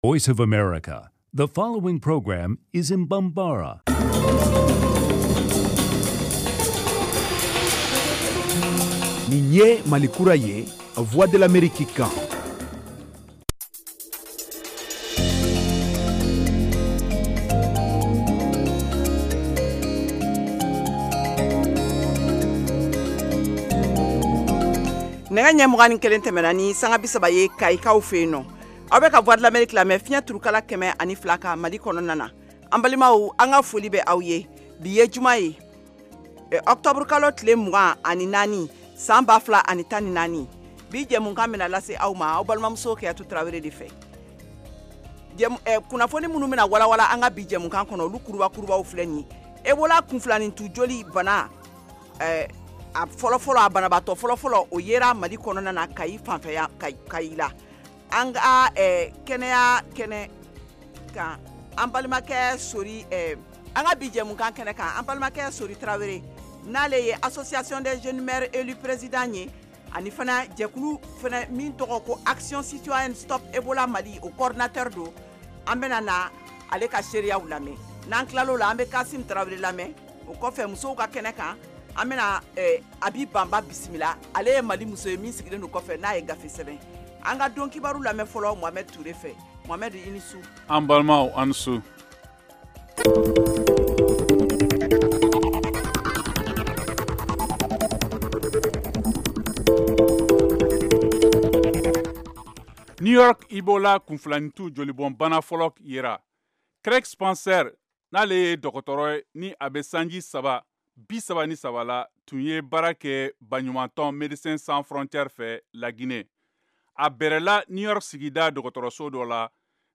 en direct de Washington